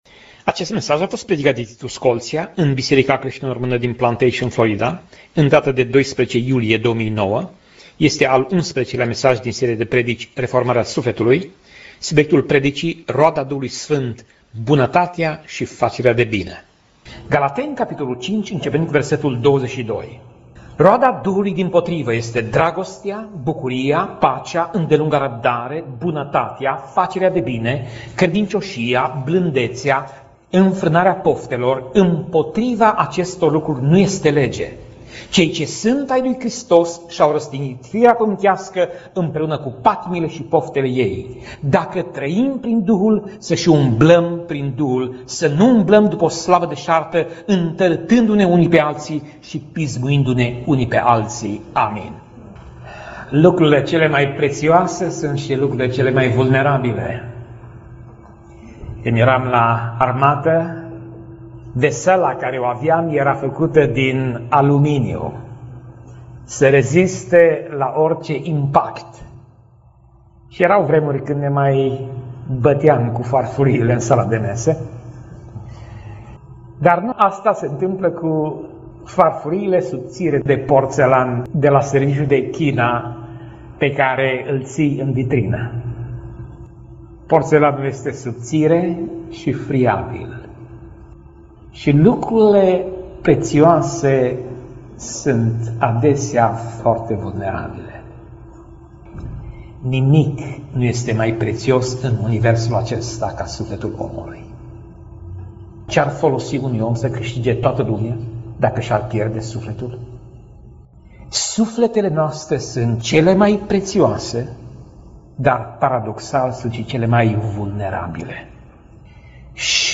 Pasaj Biblie: Galateni 5:22 - Galateni 5:26 Tip Mesaj: Predica